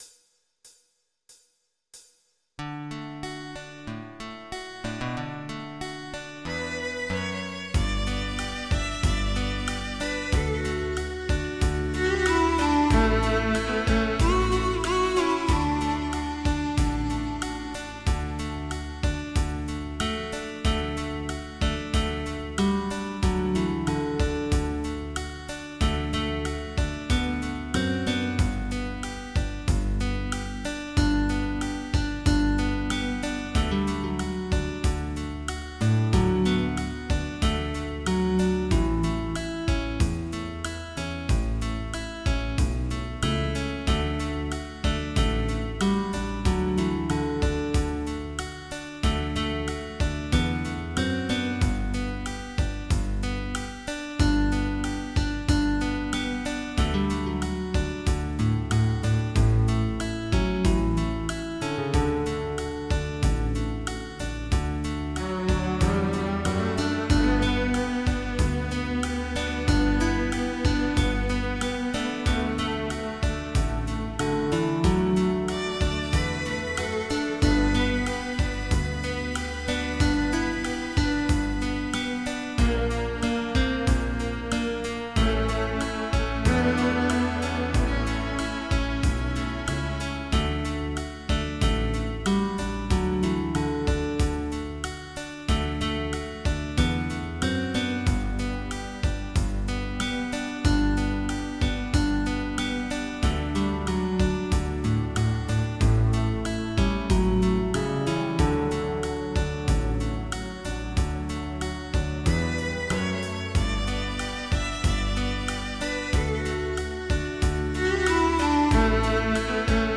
Oldies
MIDI Music File